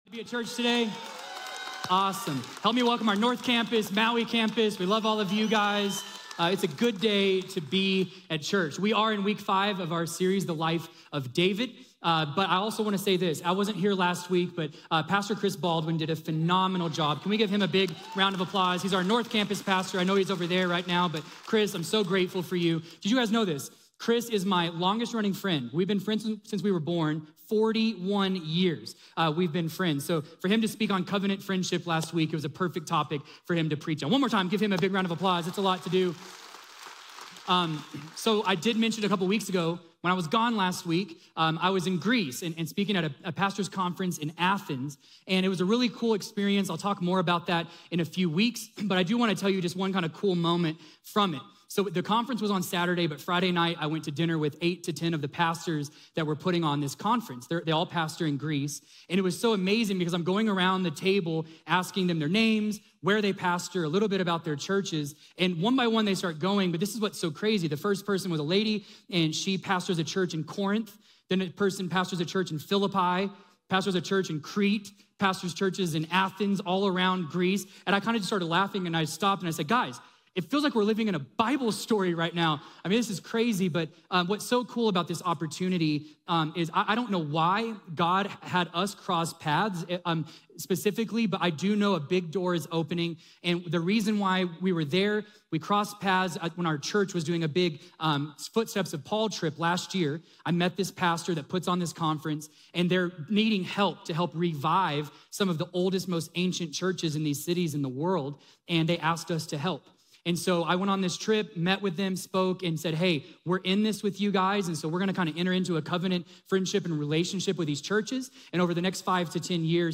In this leadership sermon series, we're exploring the timeless wisdom and principles found in the scriptures that can guide and inspire leaders in all areas of life.